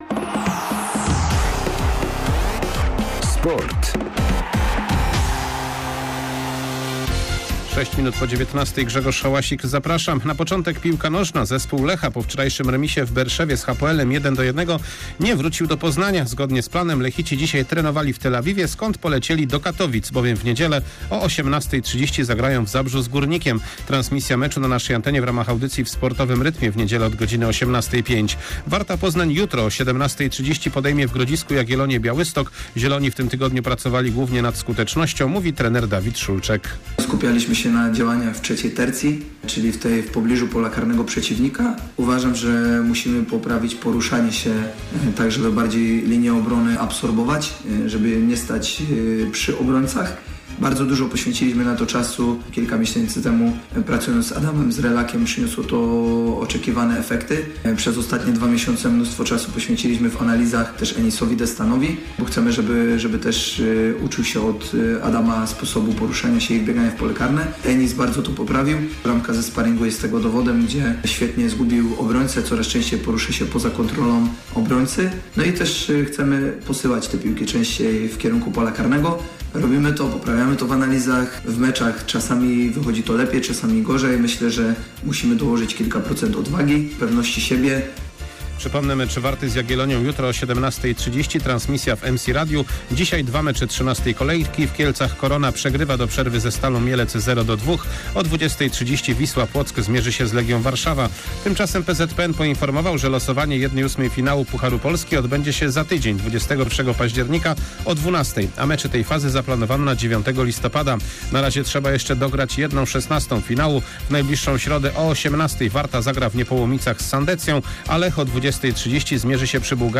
14.10.SERWIS SPORTOWY GODZ. 19:05